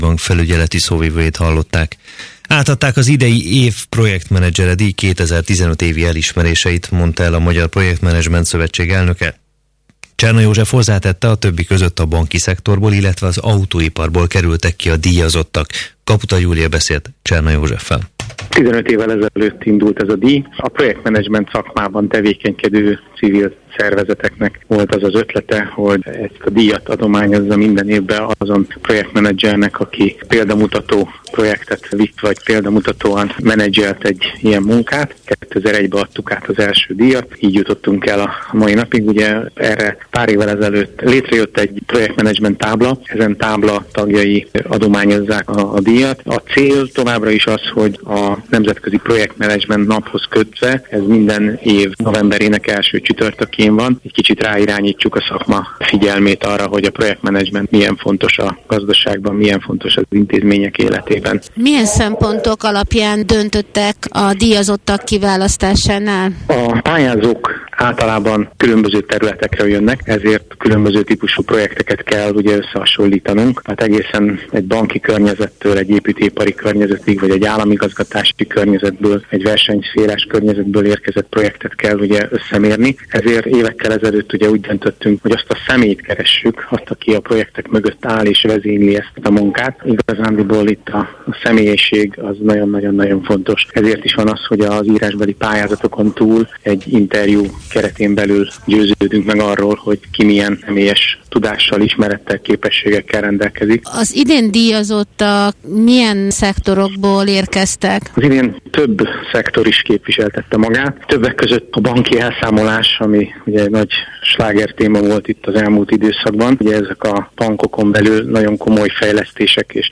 InfoRadio-interju1.mp3